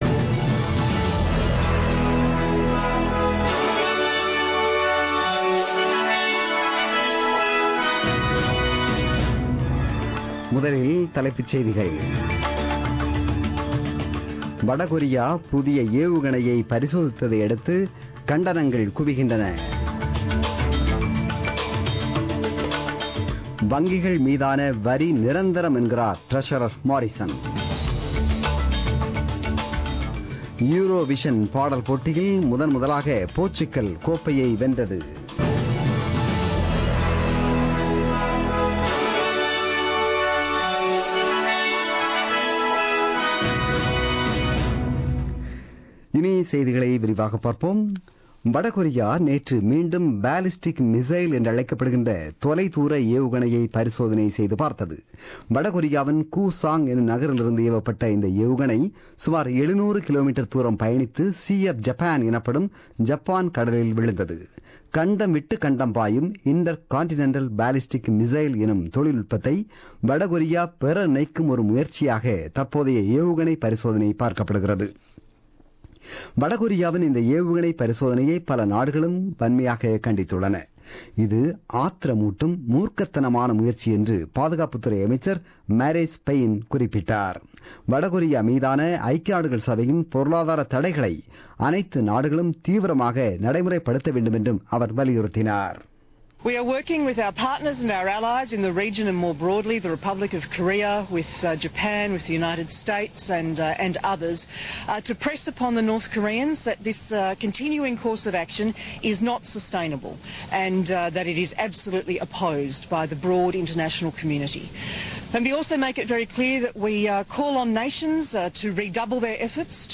The news bulletin broadcasted on 14 May 2017 at 8pm.